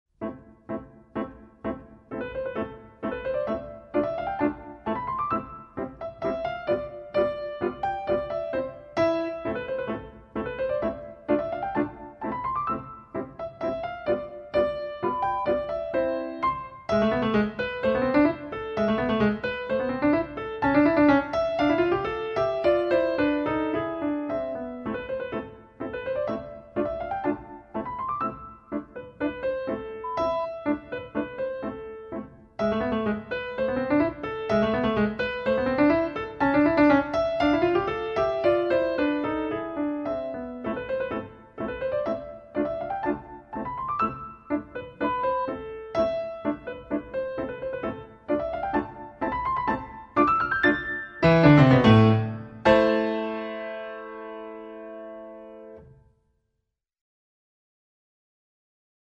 Etude pour Piano